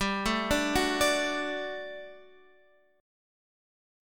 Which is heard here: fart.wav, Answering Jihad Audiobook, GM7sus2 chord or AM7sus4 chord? GM7sus2 chord